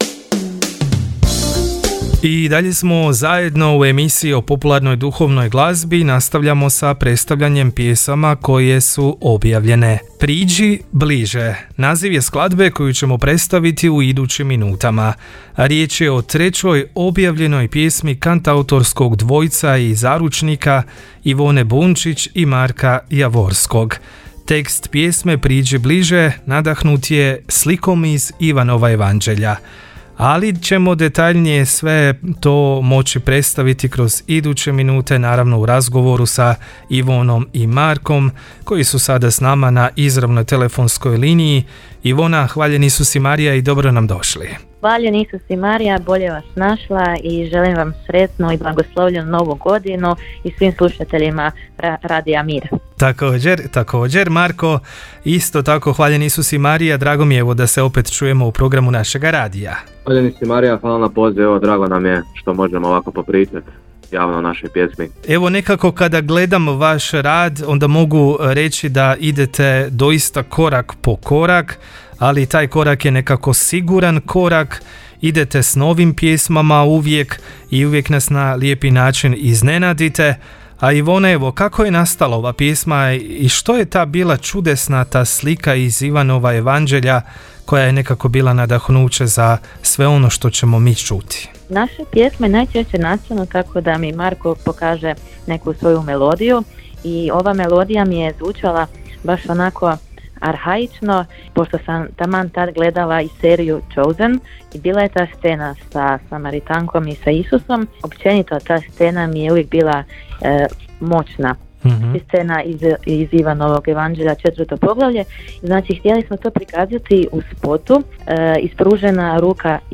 gosti emisije Popsacro Val